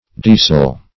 \Die"sel mo`tor\ (d[=e]"zel).